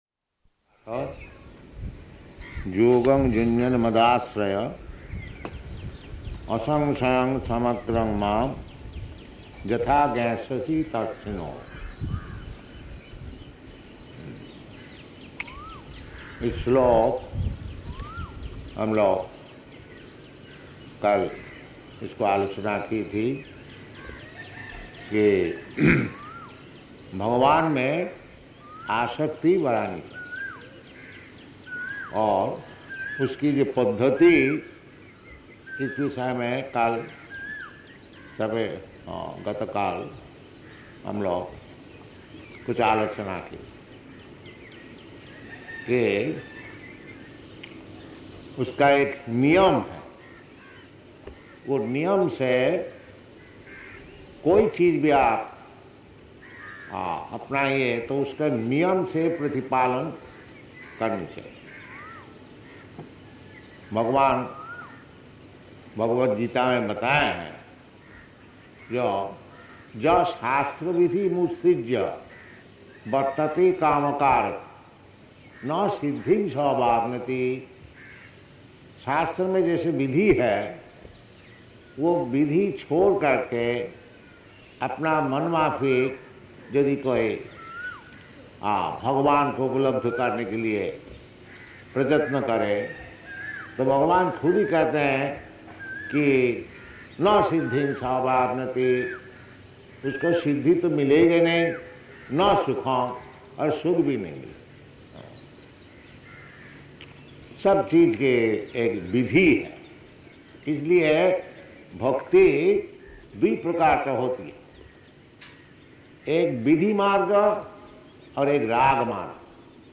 Location: Bombay
Pandal Lecture